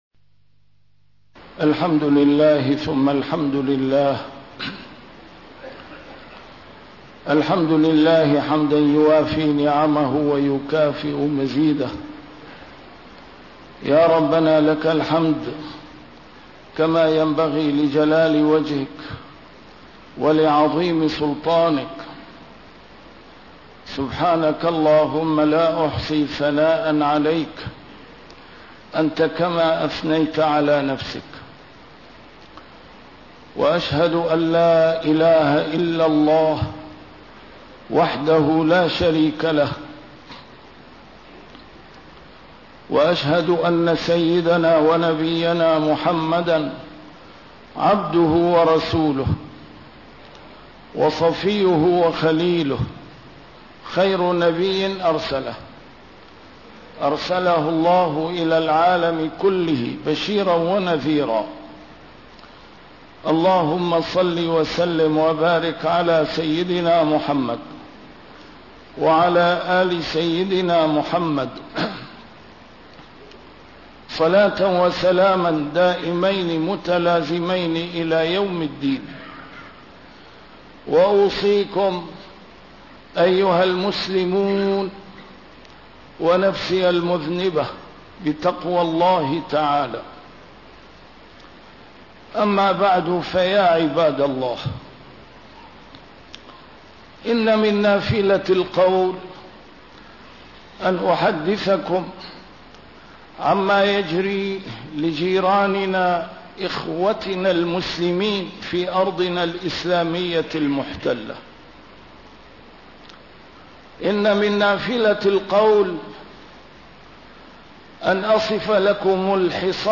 A MARTYR SCHOLAR: IMAM MUHAMMAD SAEED RAMADAN AL-BOUTI - الخطب - إخواننا بين الموت والحياة ونحن ساهون لاهون